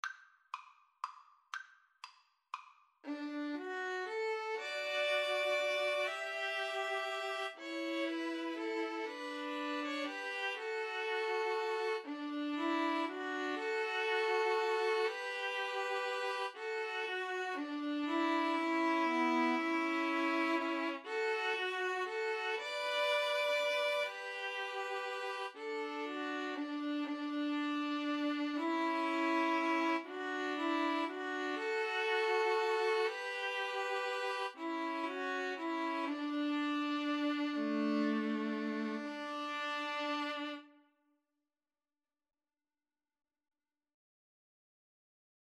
3/4 (View more 3/4 Music)
D major (Sounding Pitch) (View more D major Music for String trio )
= 120 Slow one in a bar
String trio  (View more Easy String trio Music)